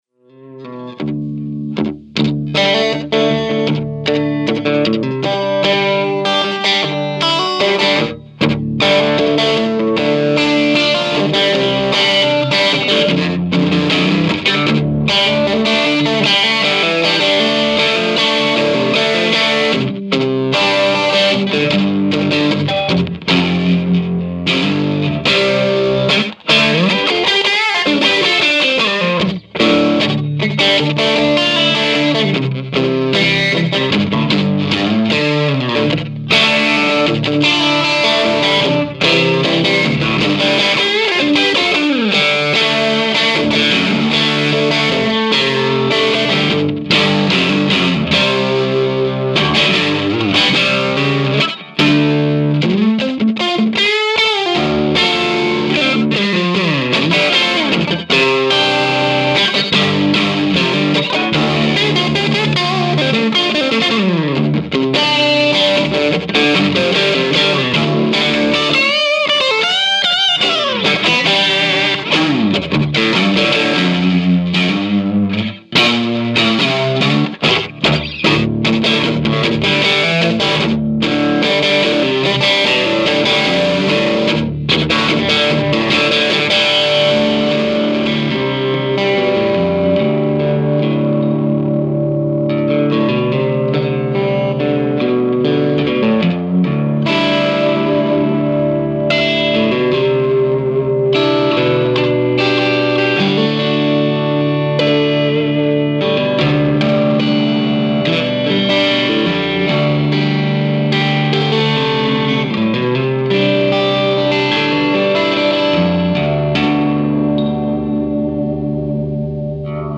This is my sIII with the boost on, TMB channel only. The guitar is a strat.
Trinity 2x12 with 2 tone tubby ceramics
Mike - SM-57 About 18 inches from cab
Guitar - 62ri Strat
and with effects (comp-modulated echo-stereo imager-volume maximizer)
Strat_sIII_BoostOn_fx.mp3